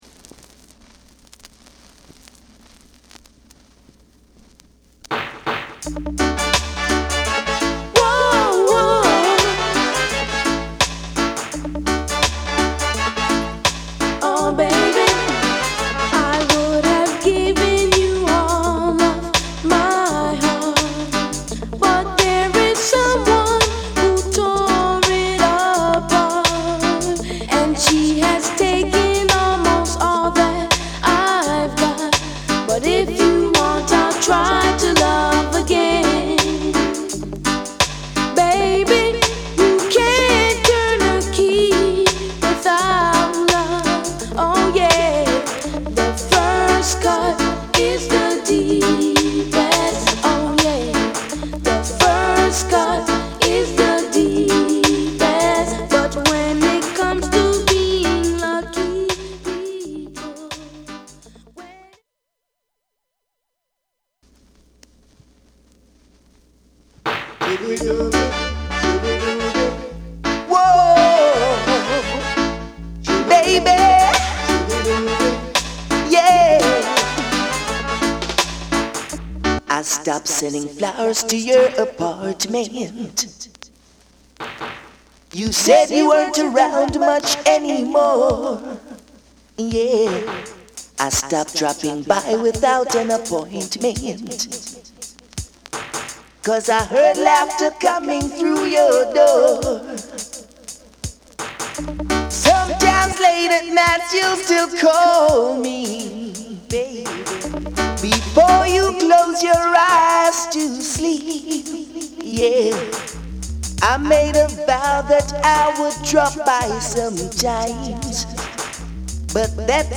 REGGAE / DANCEHALL
盤は擦れや音に影響がある傷がわずかですが有り使用感が感じられます。